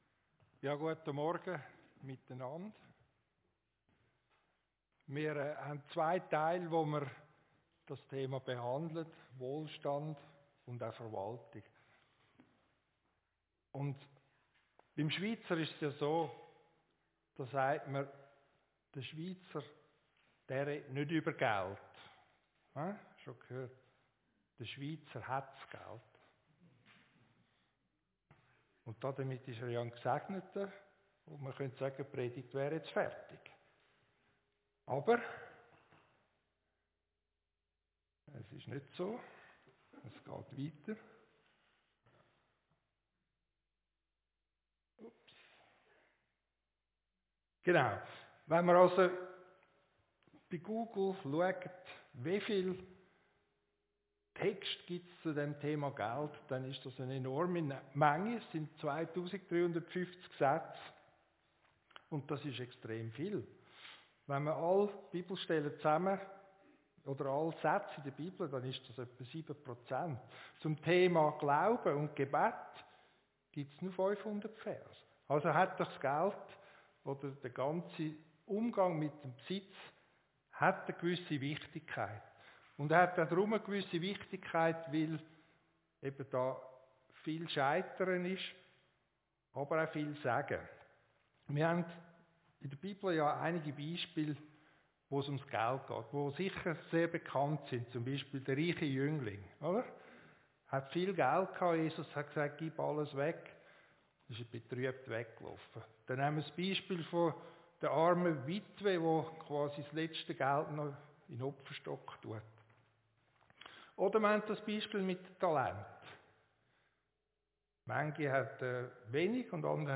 Predigt-2.6.24.mp3